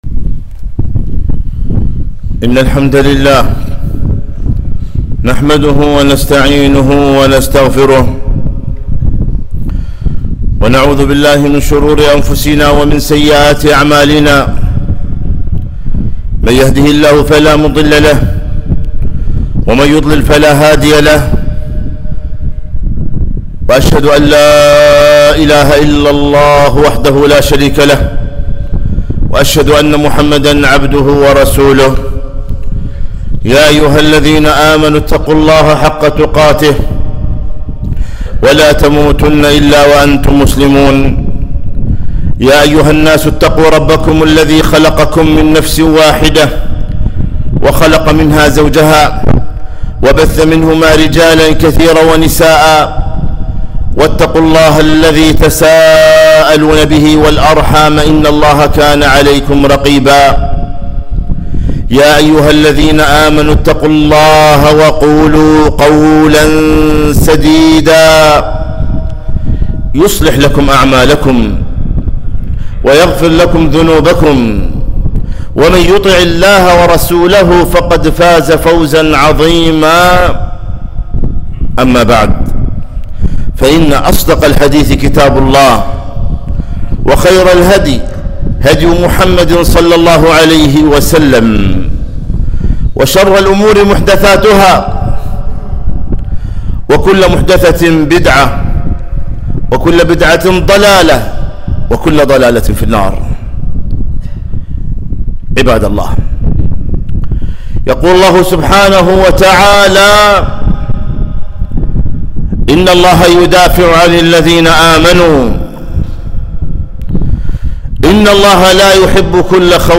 خطبة - ( ألا إن نصر الله قريب )